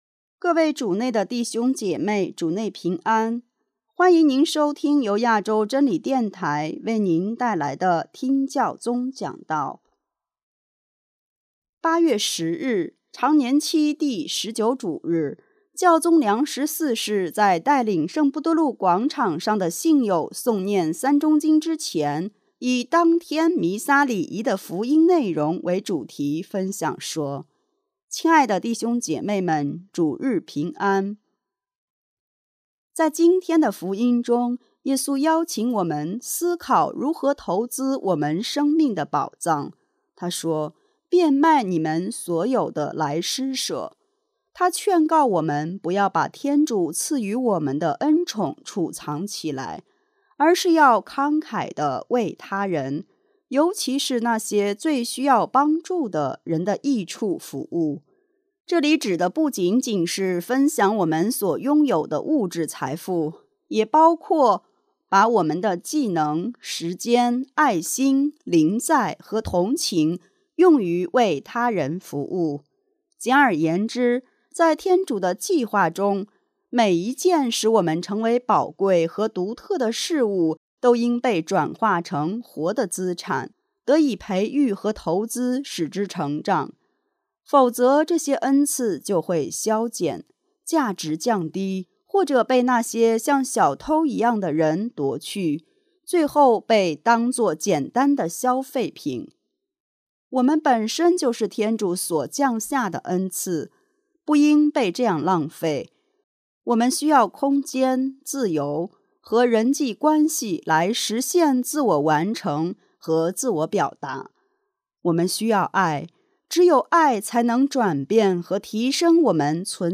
【听教宗讲道】| 我们本身即为上天之恩赐，回报是那永恒的生命
810日，常年期第19主日。